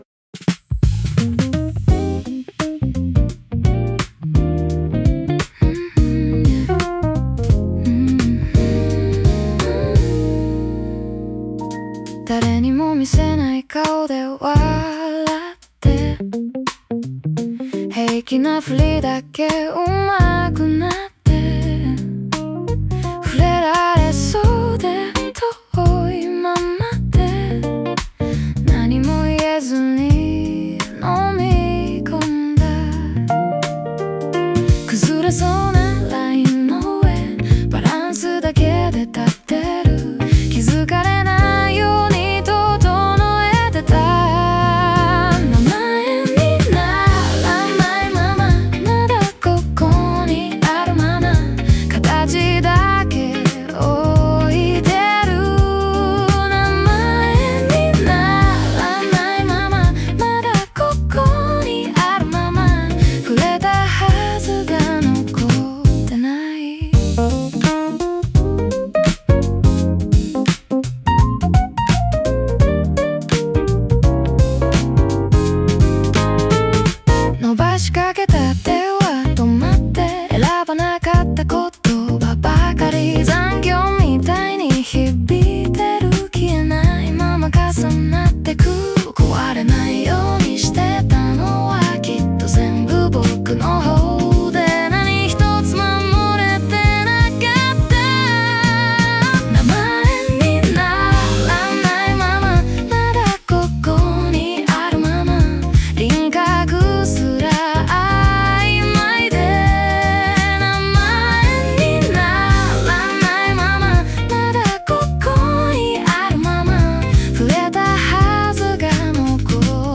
女性ボーカル